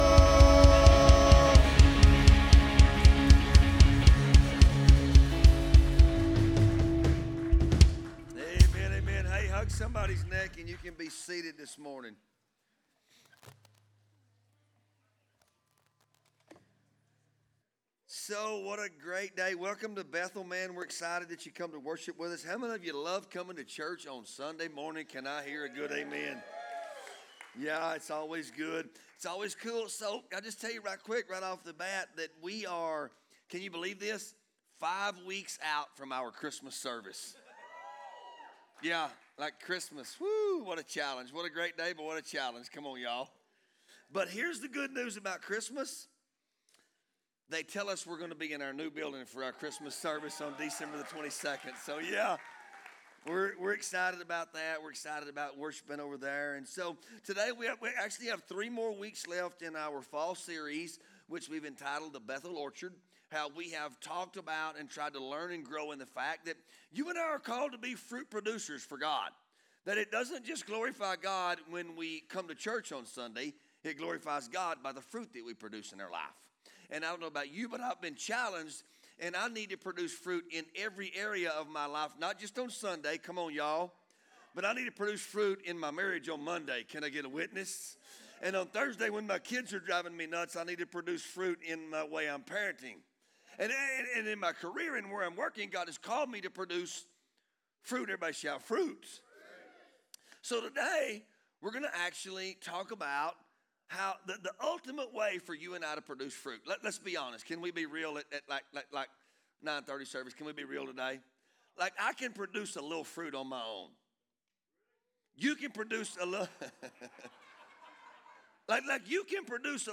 Sermons | Bethel Assembly of God Church